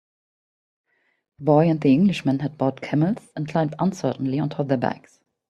Pronounced as (IPA) /ˈkæməlz/